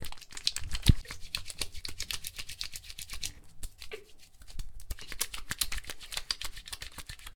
Lavándose frotando con agua y jabón
Grabación sonora en la que se escucha el sonido de alguien lavándose, realizando higiene personal, frotandose con sus manos con agua y jabón.
Sonidos: Acciones humanas